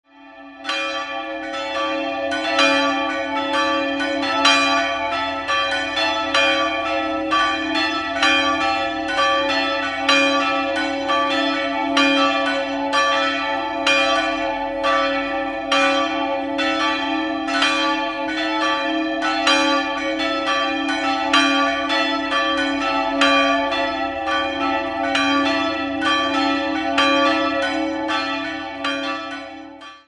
3-stimmiges Paternoster-Geläute: d''-e''-fis''